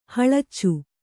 ♪ haḷaccu